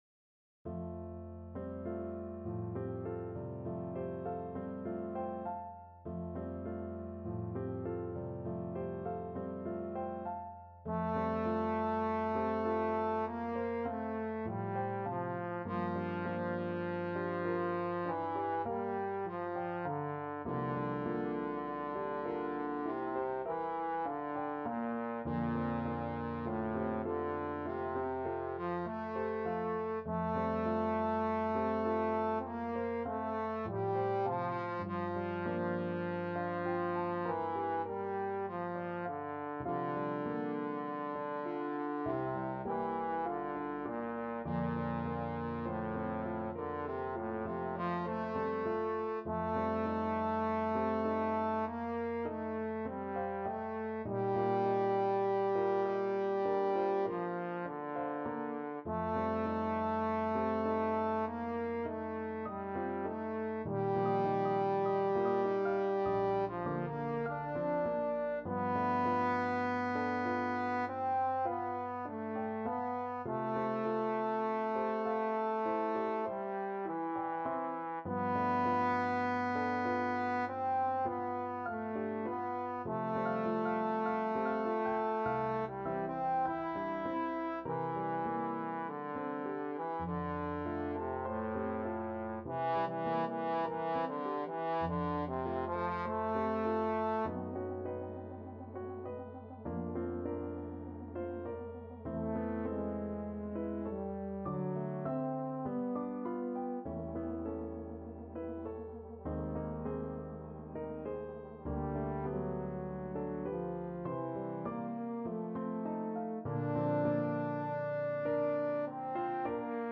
Classical Rimsky-Korsakov, Nikolai Nocturne from Pan Voyevoda Trombone version
Trombone
F major (Sounding Pitch) (View more F major Music for Trombone )
Lento =50
Classical (View more Classical Trombone Music)